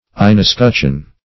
Search Result for " inescutcheon" : The Collaborative International Dictionary of English v.0.48: Inescutcheon \In`es*cutch"eon\, n. (Her.) A small escutcheon borne within a shield.